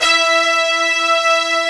Index of /90_sSampleCDs/AKAI S-Series CD-ROM Sound Library VOL-1/BRASS SECT#1